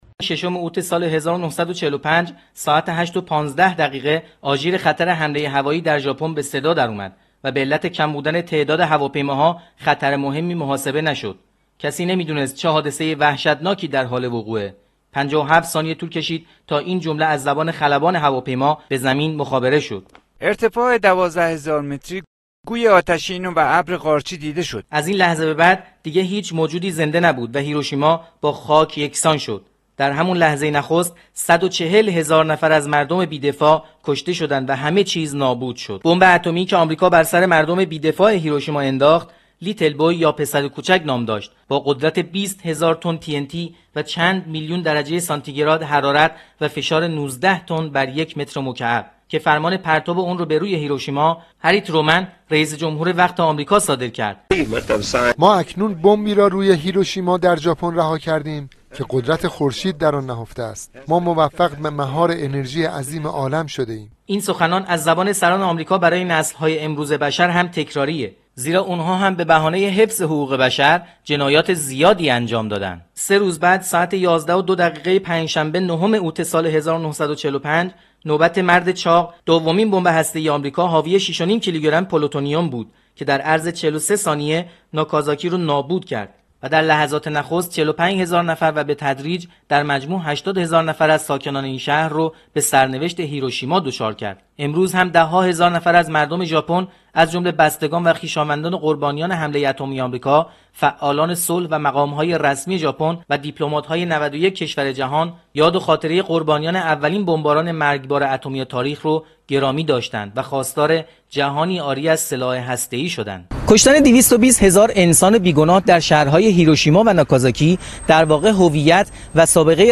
Гузориши ҳамкорамон